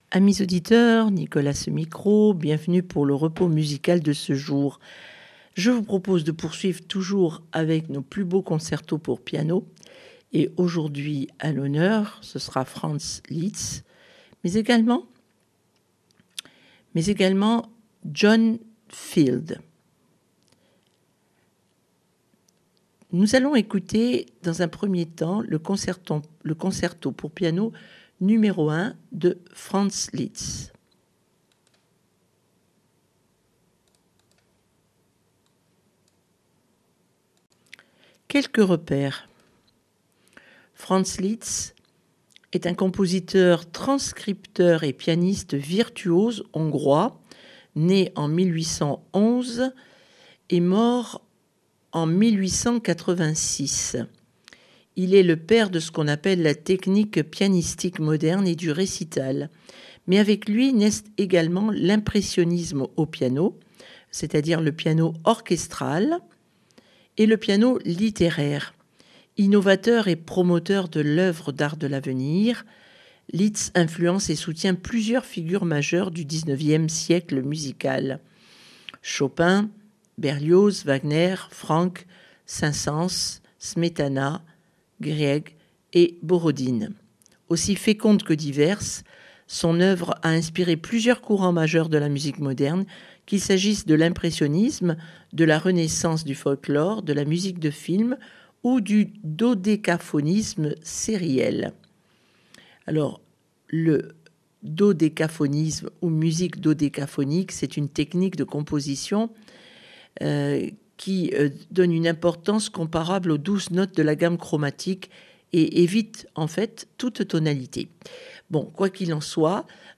Concertos piano Litz Field